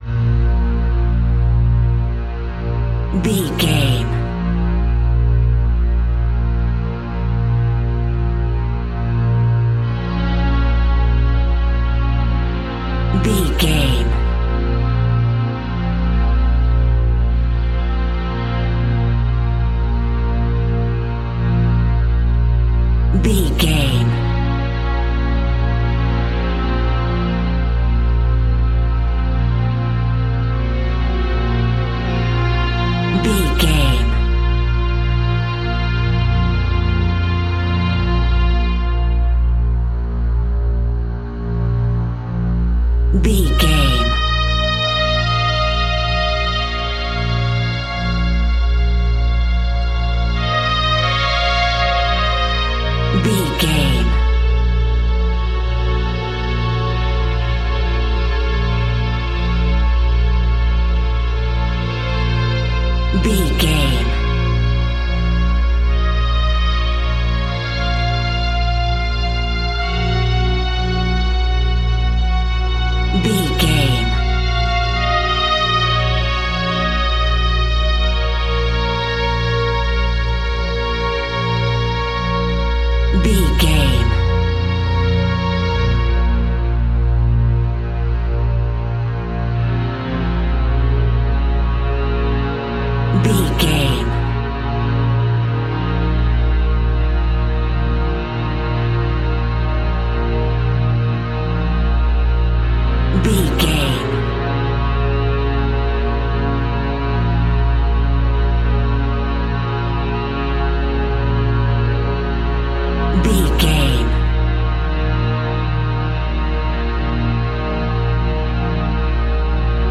Aeolian/Minor
B♭
Slow
ominous
suspense
haunting
eerie
strings
viola
orchestral instruments